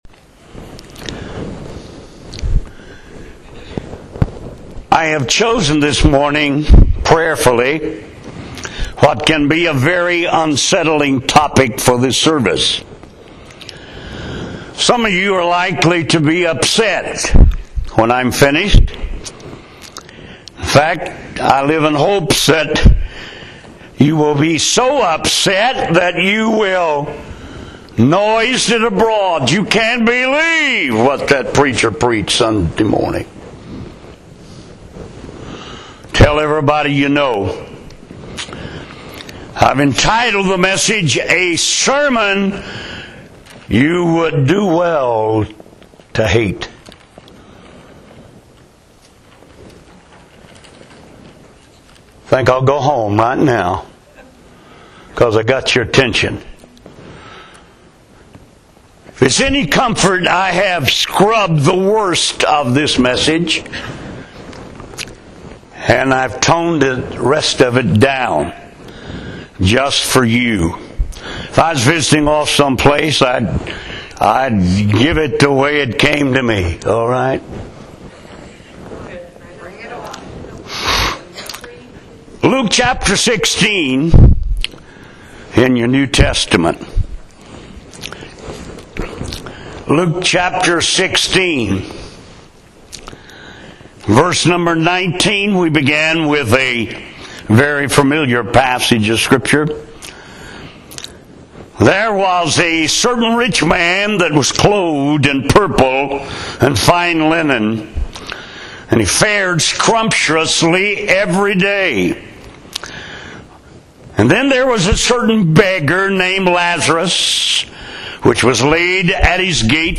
An Unforgettable Sermon on Hell